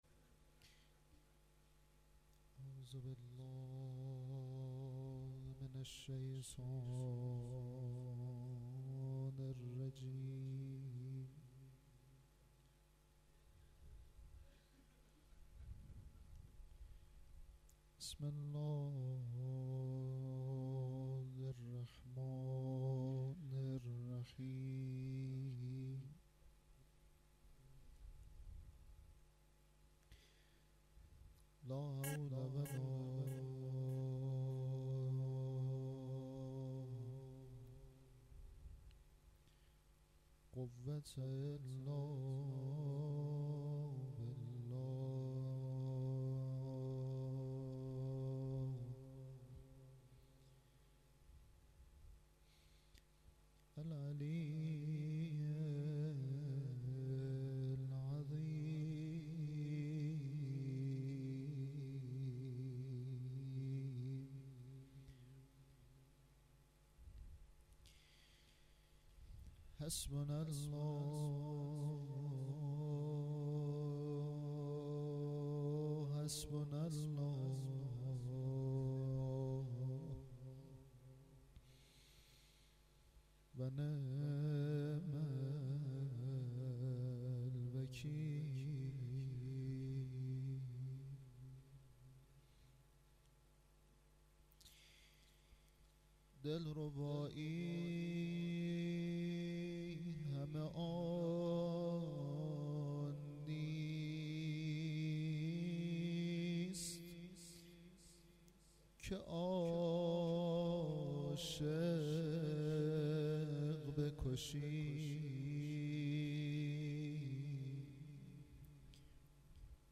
روضه
شب هشتم ماه مبارک رمضان 1392 - هیات لثارات الحسین - حوزه علمیه نخبگان
roze.mp3